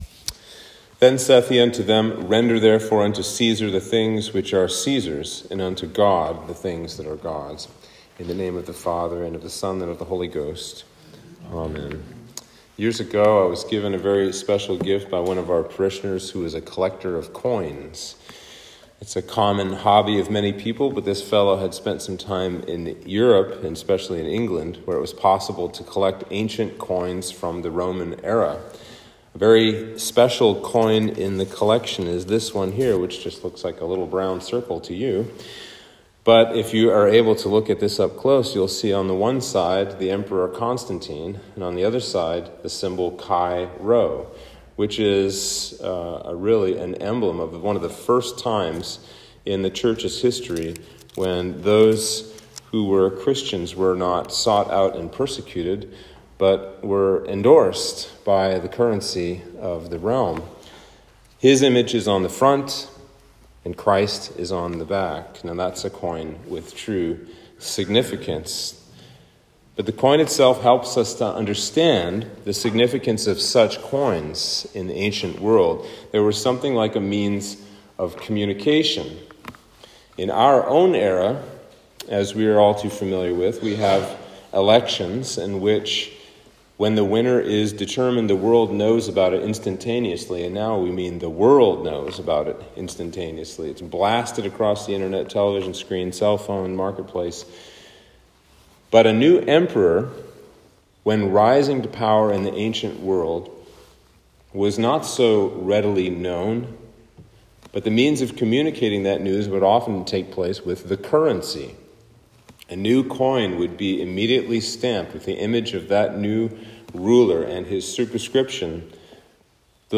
Sermon for Trinity 23